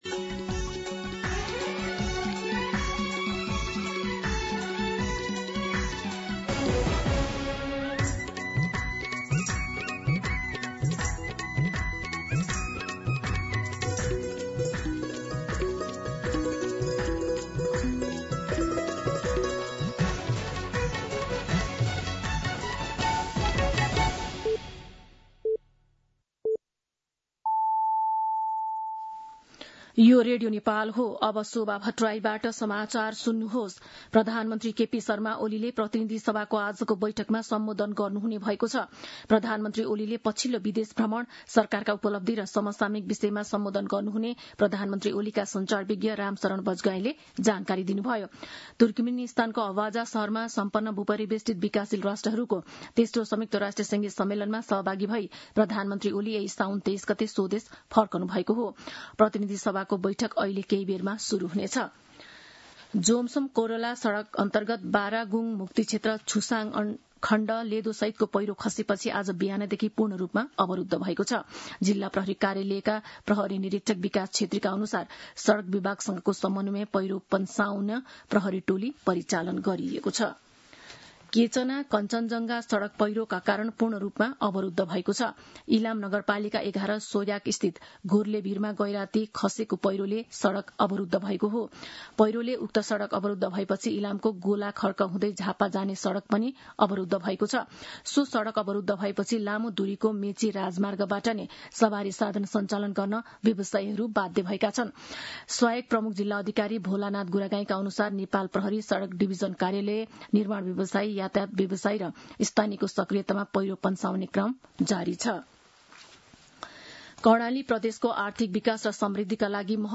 दिउँसो १ बजेको नेपाली समाचार : २८ साउन , २०८२